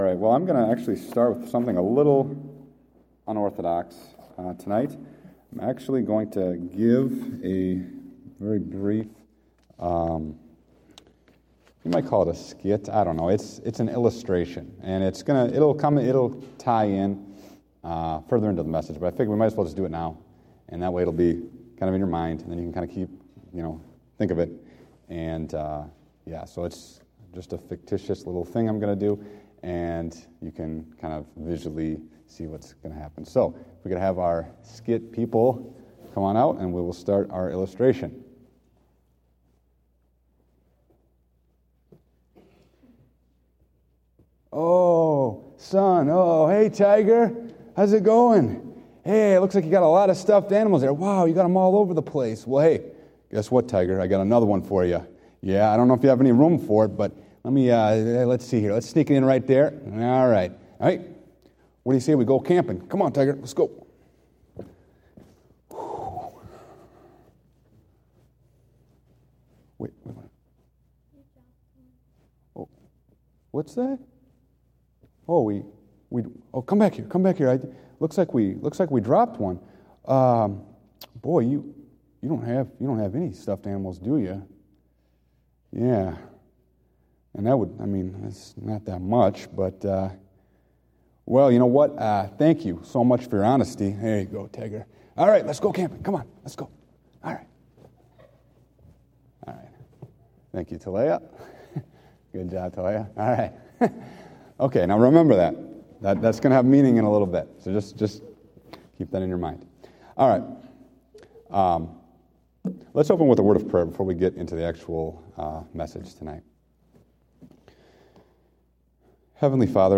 Date: December 6, 2015 (Evening Service)